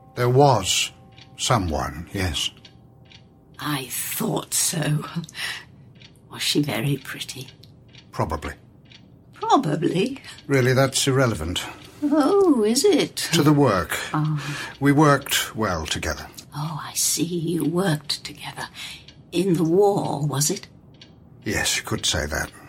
Audio drama
Memorable Dialog